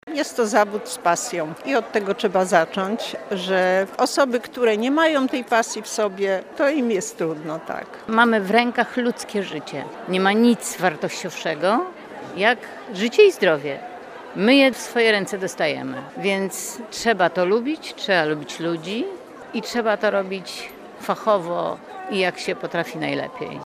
Za co swoją pracę lubią pomorskie pielęgniarki? Zapytał o to nasz reporter.